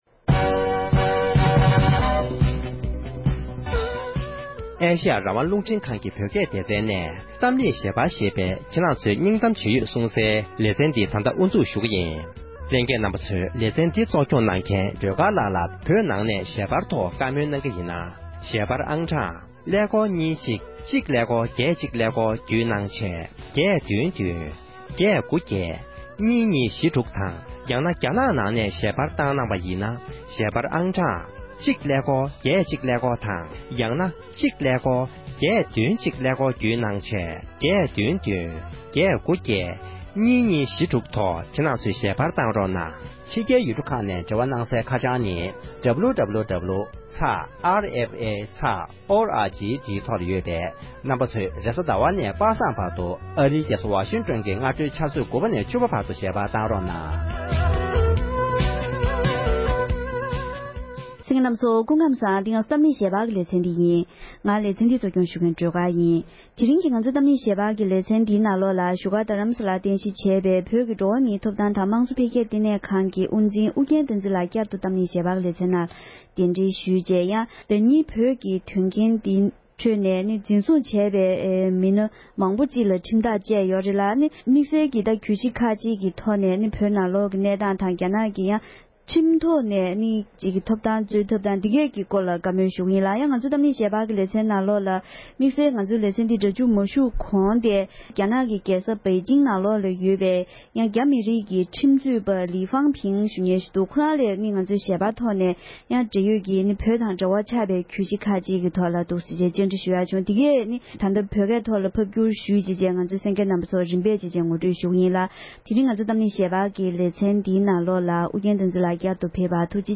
ཐེངས་འདིའི་གཏམ་གླེང་ཞལ་པར་གྱི་ལེ་ཚན